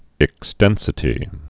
(ĭk-stĕnsĭ-tē)